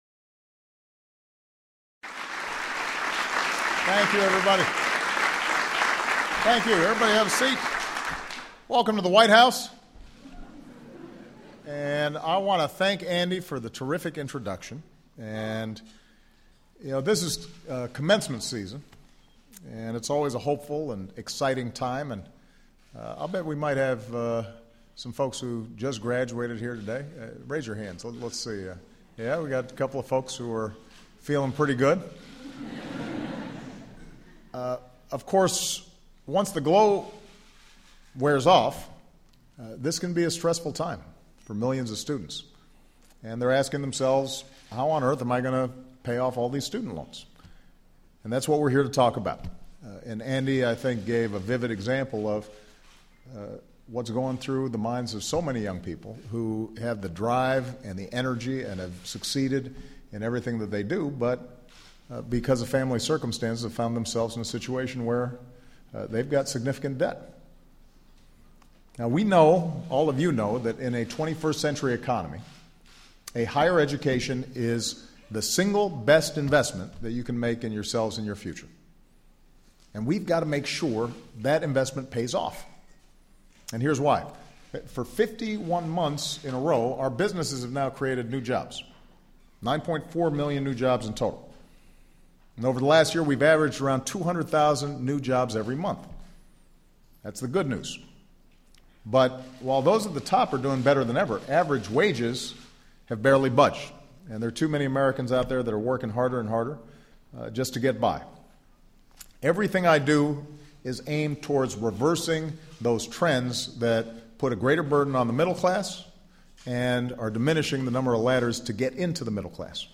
President Obama describes the need to make college more affordable, before signing a memorandum outlining new executive actions to support federal student loan borrowers. Changes include reducing monthly payments of student loan debt to ten percent of an individual's income. Obama also criticizes Republicans for not fighting for students then signs the memorandum. Held at the White House.